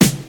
• 2000s Hip-Hop Steel Snare Drum Sound G Key 162.wav
Royality free acoustic snare sample tuned to the G note. Loudest frequency: 2532Hz